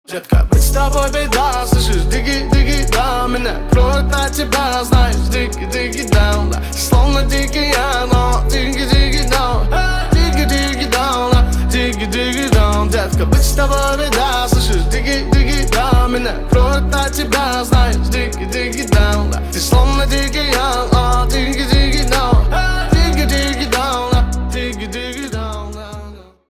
• Качество: 128, Stereo
басы
качающие